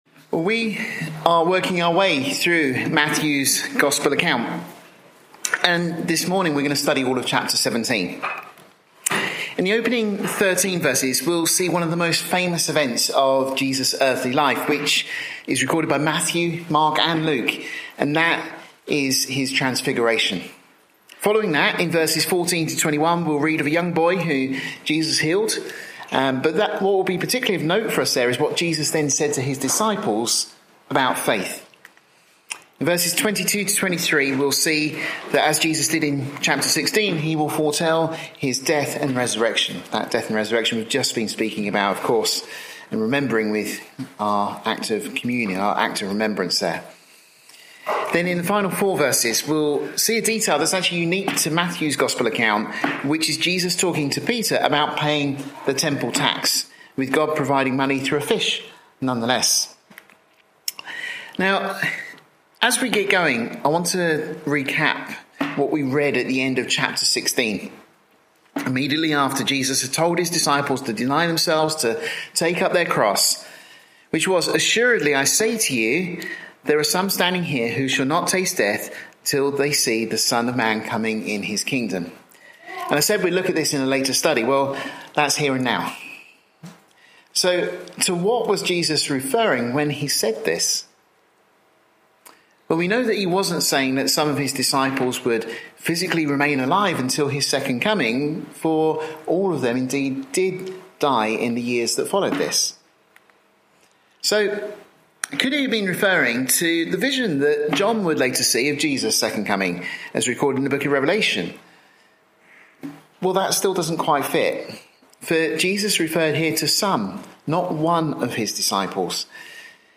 This study is part of our series of verse by verse studies of Matthew, the 40th book in the Bible.